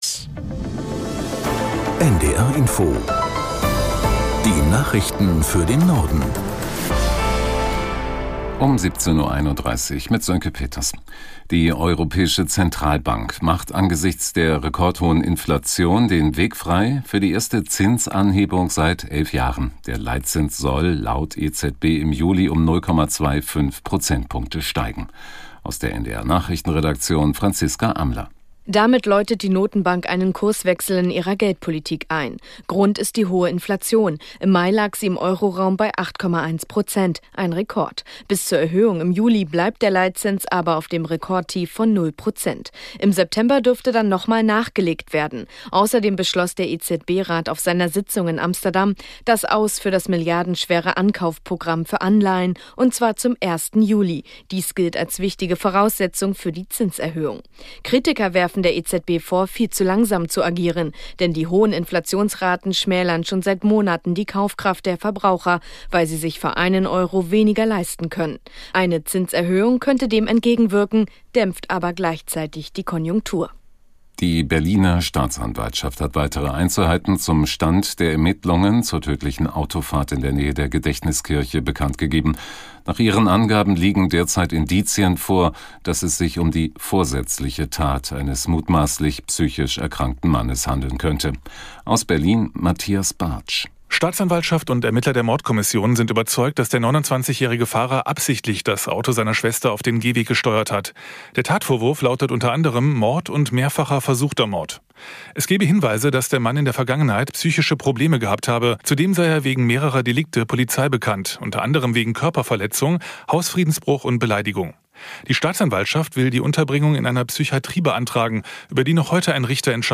Nachrichten - 02.07.2022